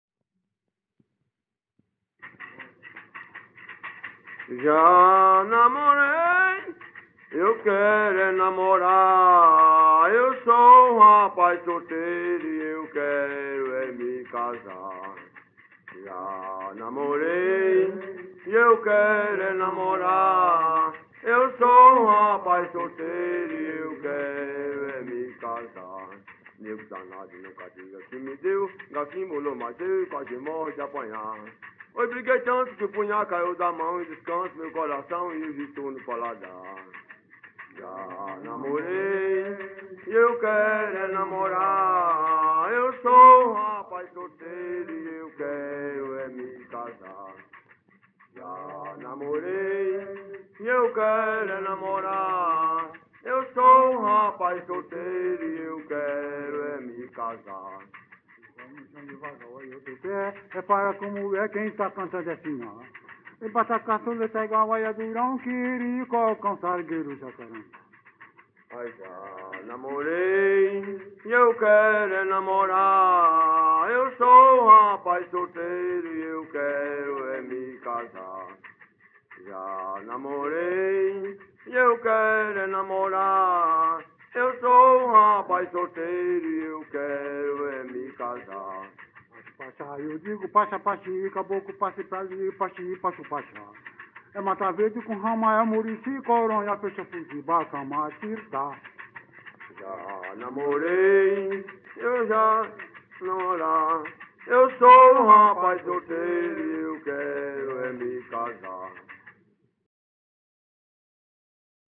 Coco- “”Ja namorei”” - Acervos - Centro Cultural São Paulo